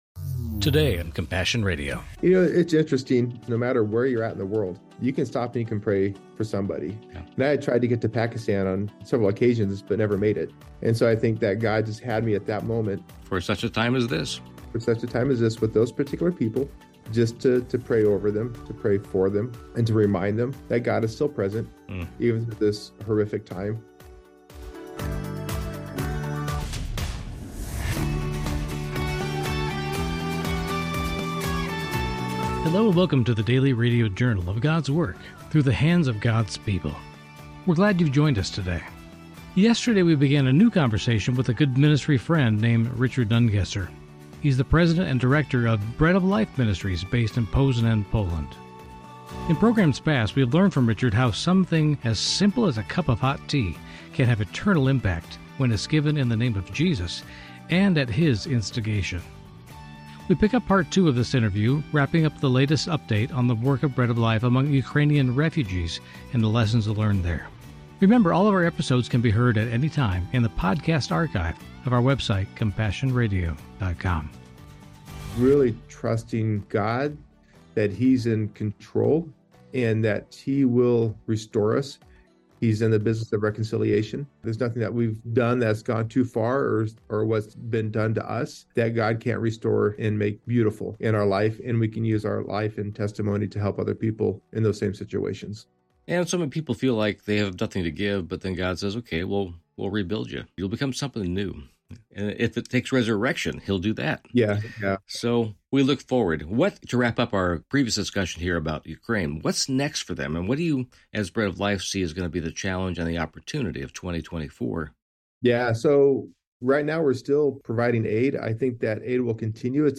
Genre: Christian News Teaching and Talk.